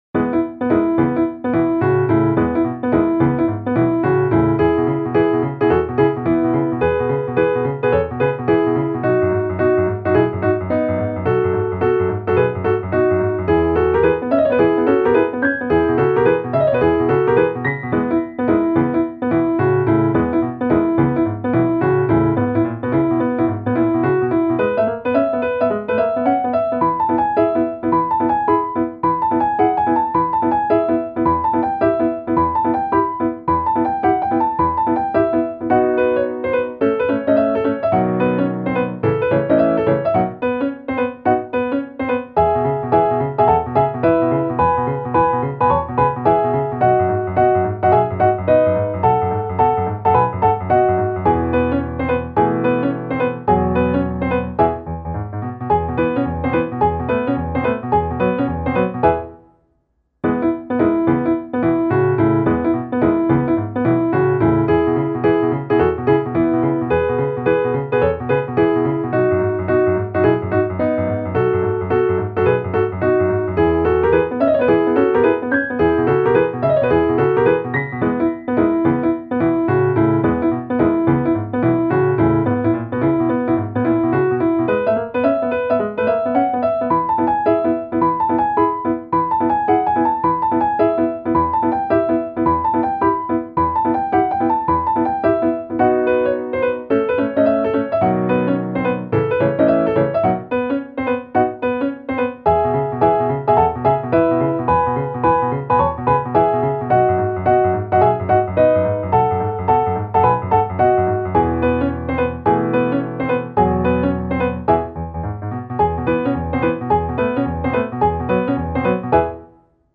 • コミカルや気軽に聞けるピアノ曲のフリー音源を公開しています。
ogg(L) - お洒落 軽やか リズミカル